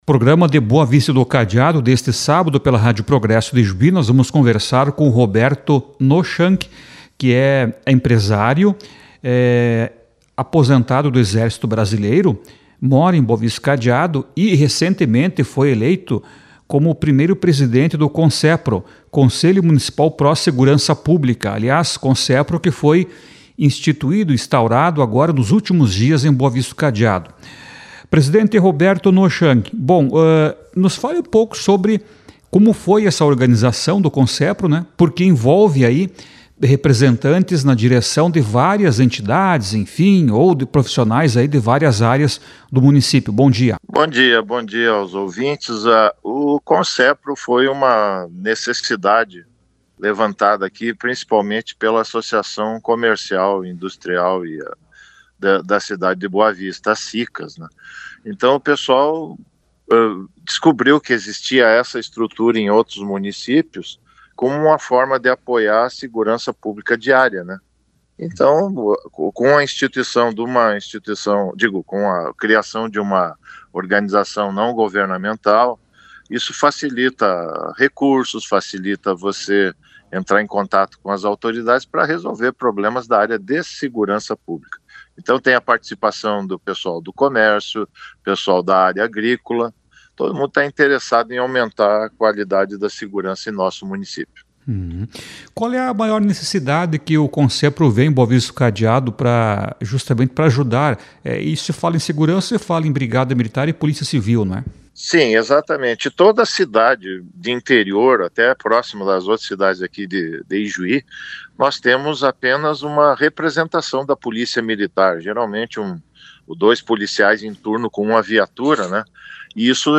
Durante entrevista nesta manhã no programa de Boa Vista do Cadeado, pela RPI, ele explicou que a entidade foi implantada a partir de necessidade levantada pela comunidade e agrega, na direção, representantes de vários segmentos.